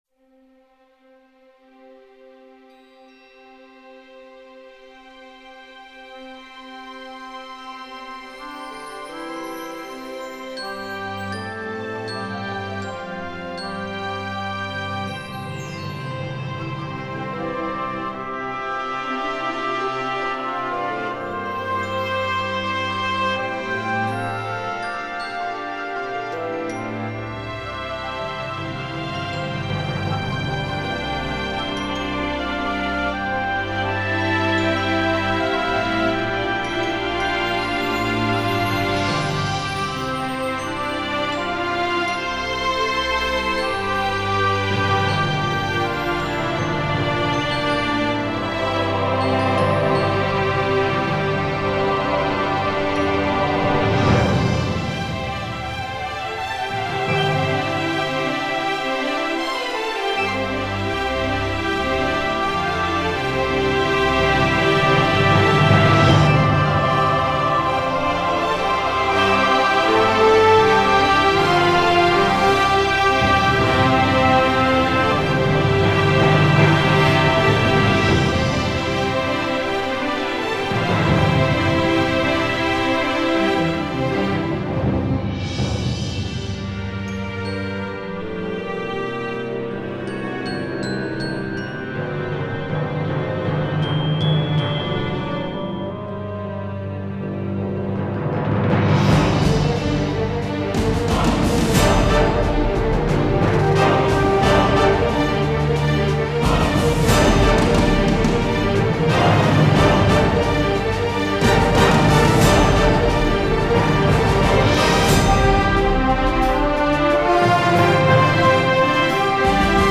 Titel Theme